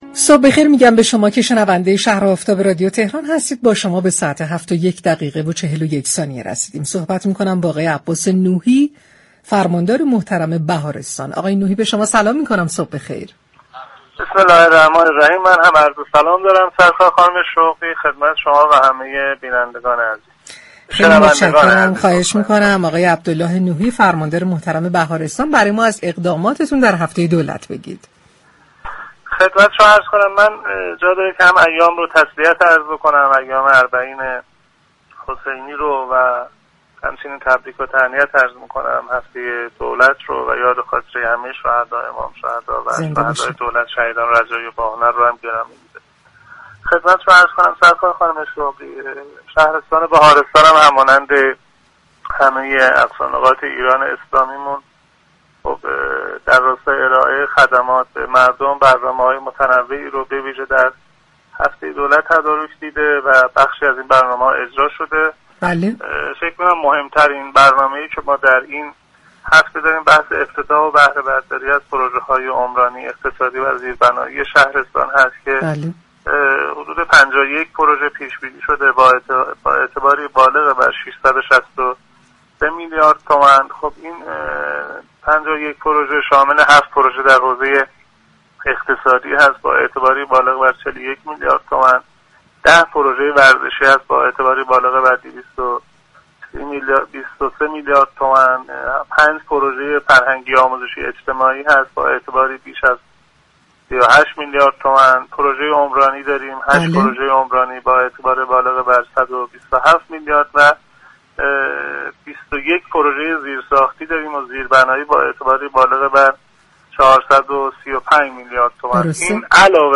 به گزارش پایگاه اطلاع رسانی رادیو تهران، عبدالله نوحی فرماندار شهرستان بهارستان در گفت و گو با «شهر آفتاب» اظهار داشت: در هفته دولت و در راستای ارایه خدمات به مردم شهرستان بهارستان، برنامه‌های متنوعی تدارك دیده شده است كه بخشی از آن اجرا شده است.